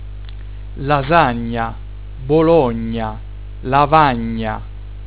Beispiele zum Nachsprechen
1.) - gn - (lasagna, Bologna, lavagna)
[ ŋ ]